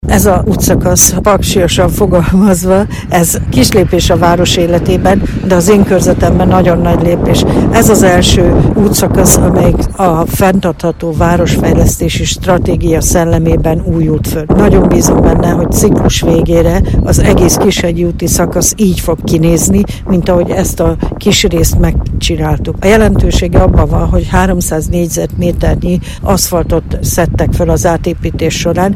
A részletekről Kudorné Szanyi Katalin önkormányzati képviselő nyilatkozott rádiónknak.